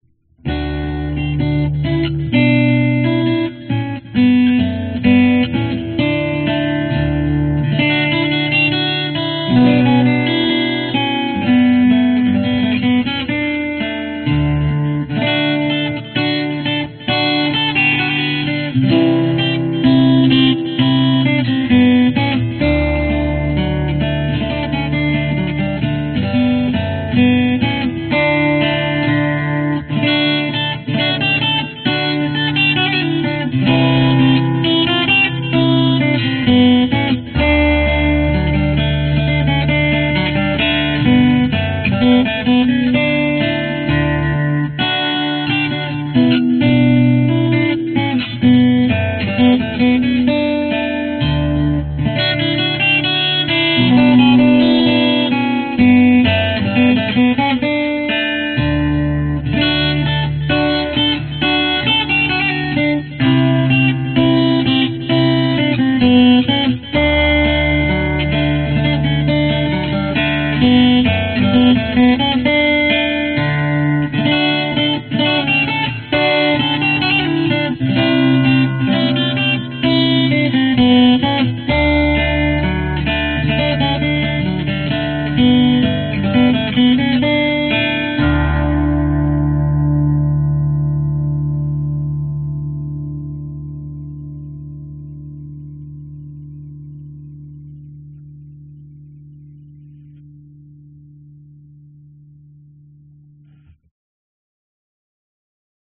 标签： Funny Improvised Jig Guitar
声道立体声